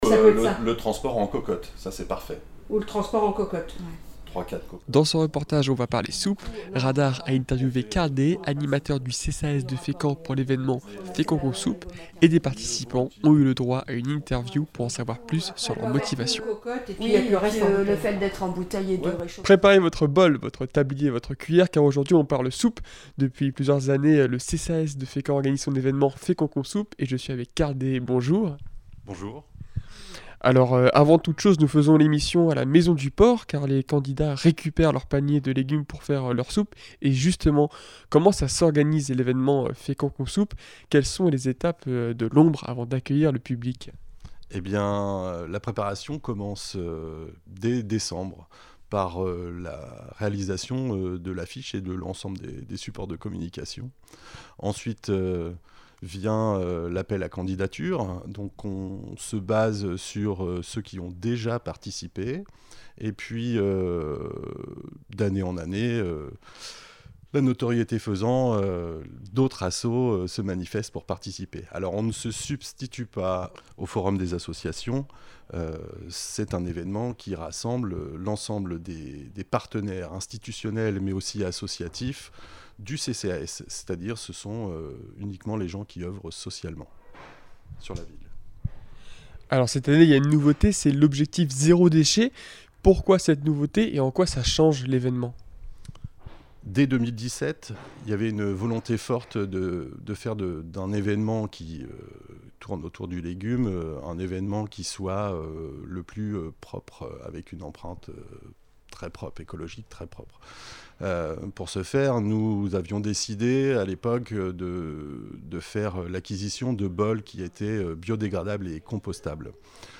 Nous avons eu la chance d’interviewer des personnes venues chercher leur panier de légumes pour les interroger sur leur participation.